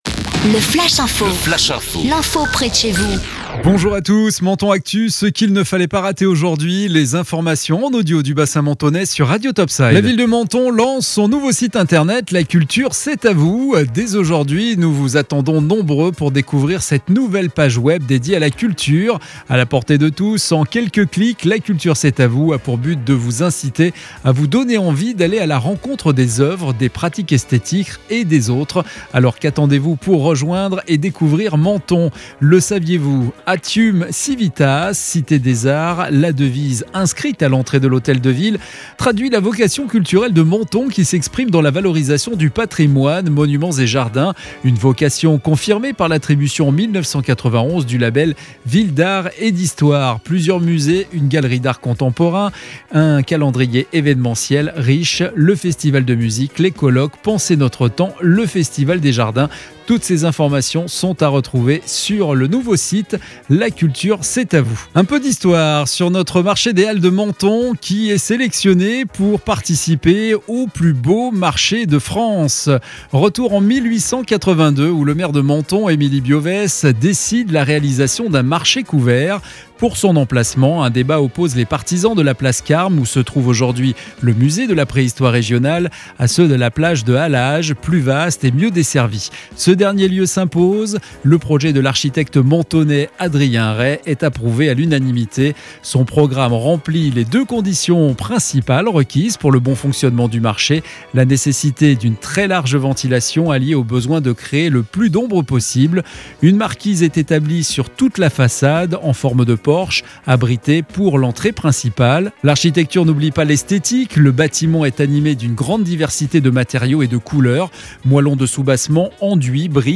Menton Actu - Le flash info du vendredi 9 avril 2021